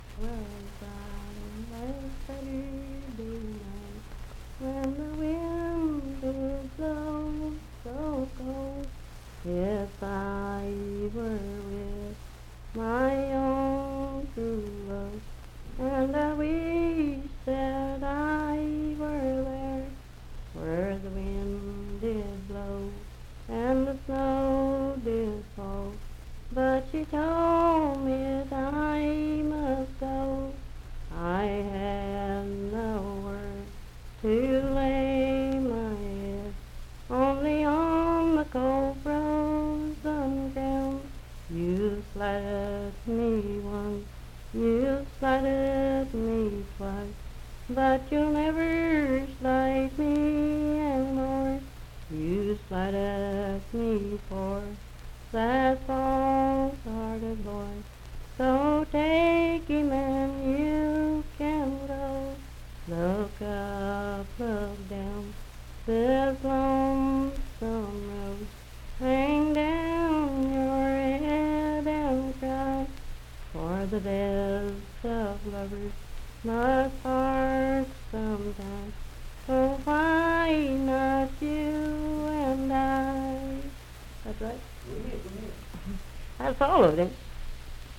Unaccompanied vocal music
Voice (sung)
Hardy County (W. Va.)